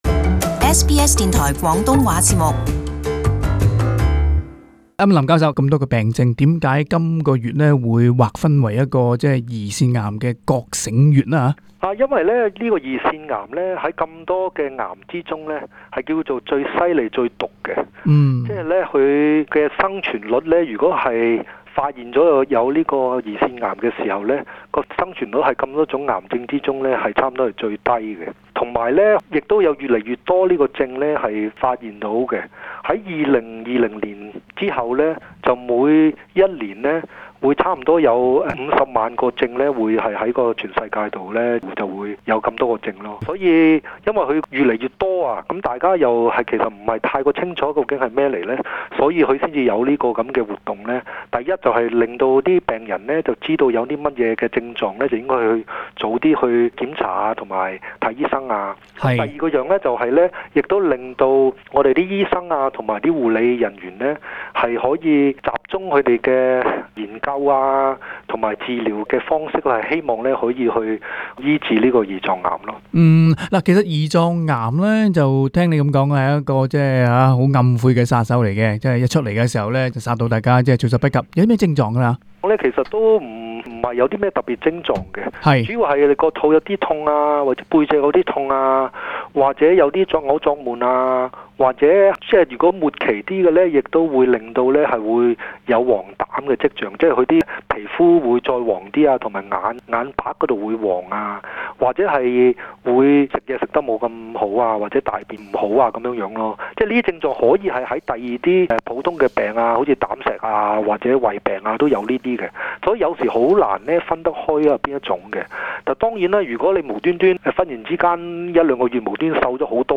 【社團專訪】關注癌症殺手胰臟癌